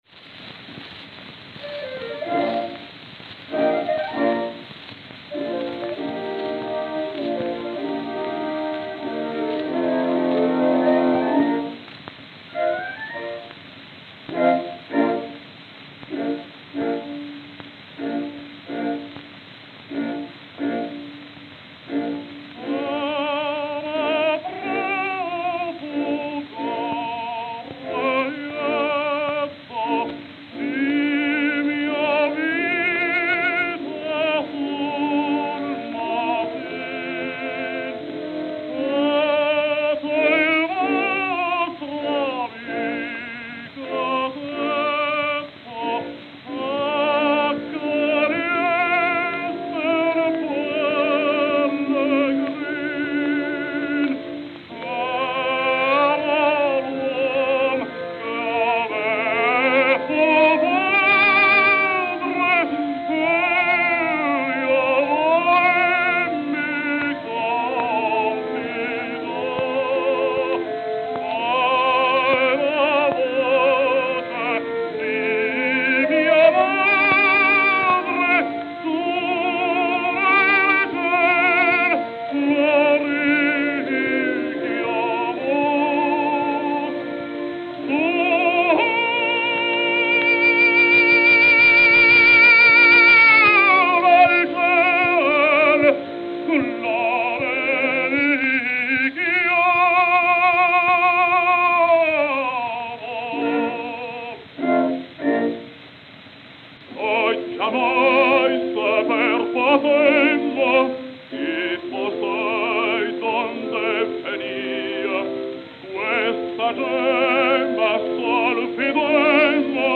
78 RPM Records
New York, New York